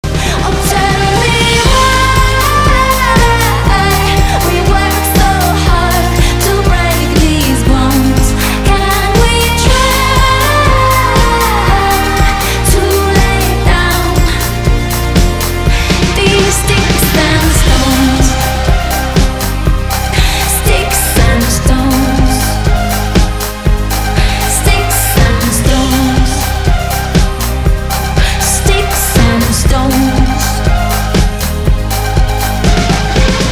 • Качество: 320, Stereo
поп
громкие